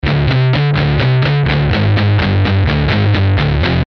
0:00 Group: Rock ( 1,037 202 ) Rate this post Download Here!